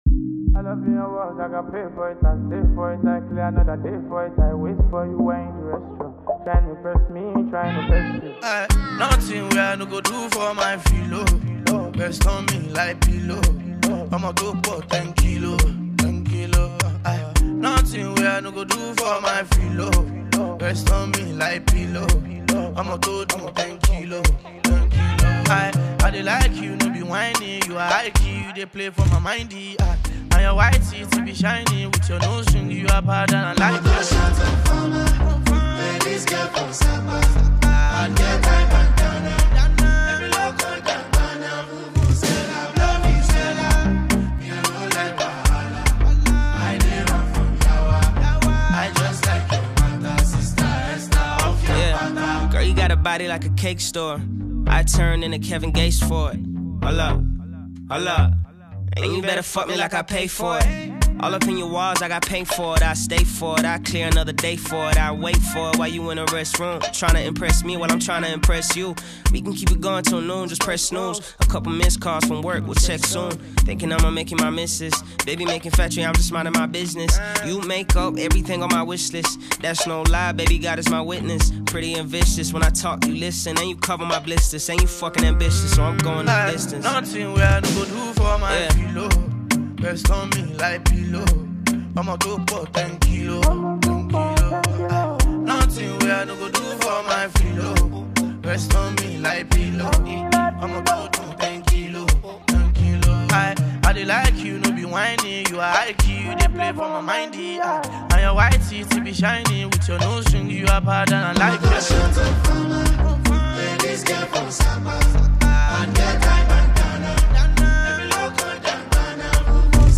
Nigerian Afrobeats expert